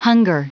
Prononciation du mot hunger en anglais (fichier audio)
Prononciation du mot : hunger